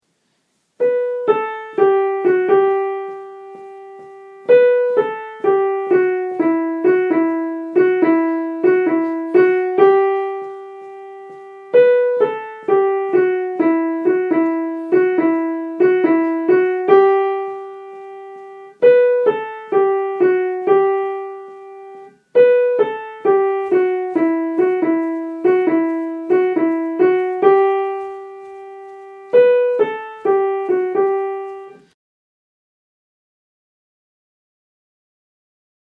Die Melodie auf dem Klavier nachgespielt tönt etwa so: